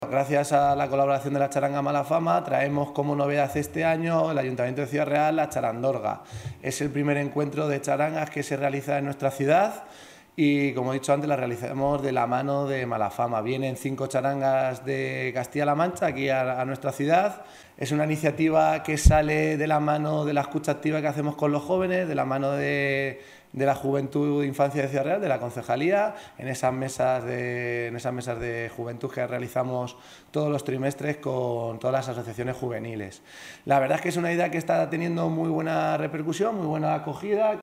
En la presentación del evento, el concejal de Juventud, Pau Beltrán, ha agradecido su implicación en la organización a los integrantes de la charanga MalaFama.
presentacion_charandorga-pau_beltran.mp3